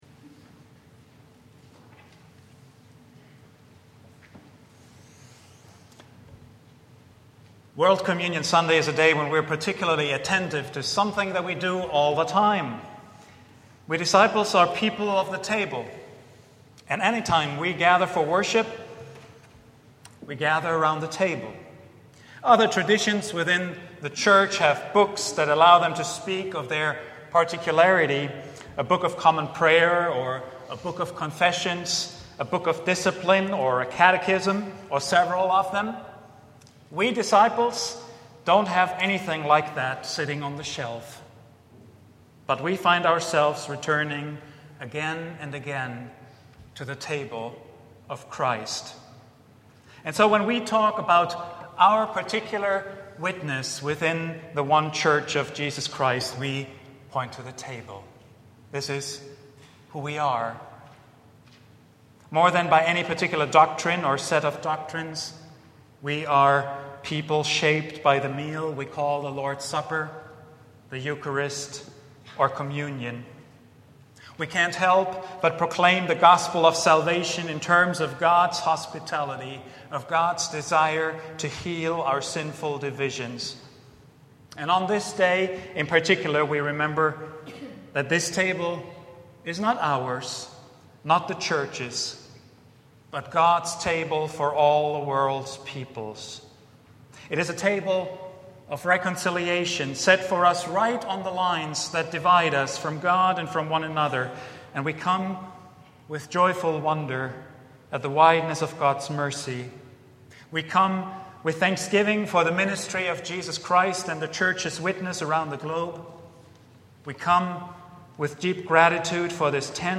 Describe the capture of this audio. A Brief Meditation on Ministry — Vine Street Christian Church